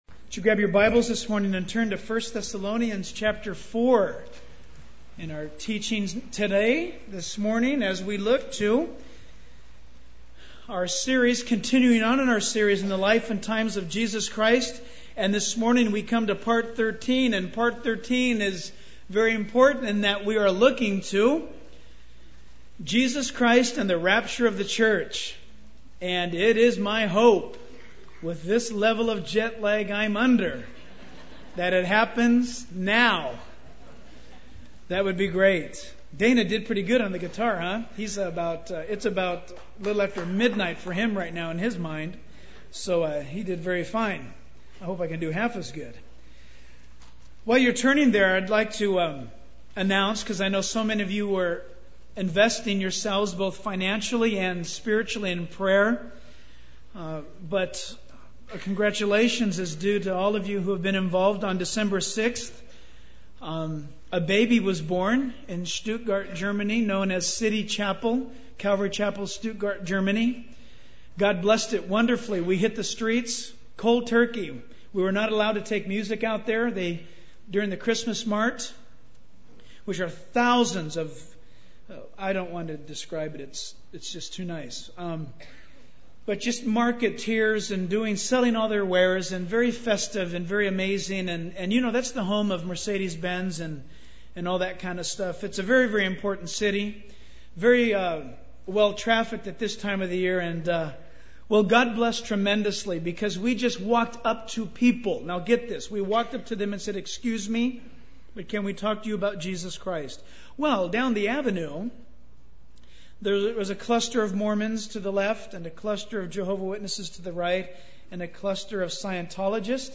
In this sermon, the speaker focuses on the topic of the rapture of the church, specifically in relation to Jesus Christ.